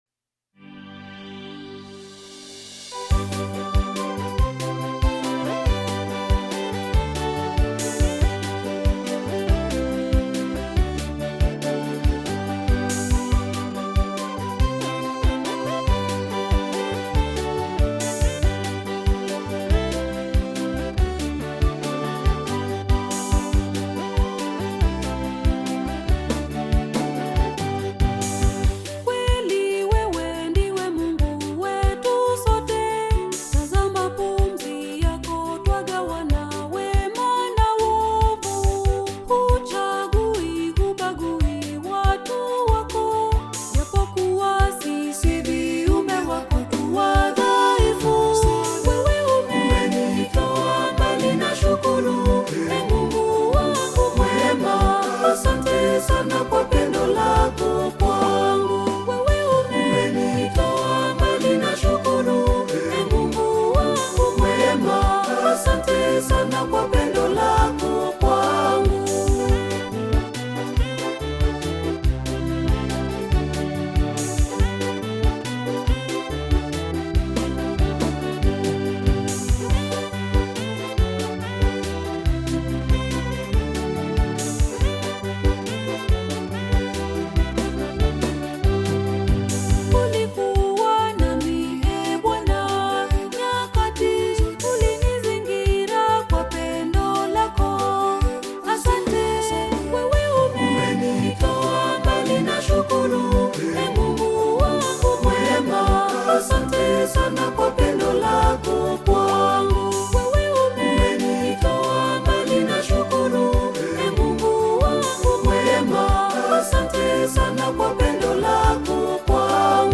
gospel song
evocative vocals
African Music